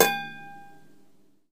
microwave ding
ding end kitchen microwave sound effect free sound royalty free Nature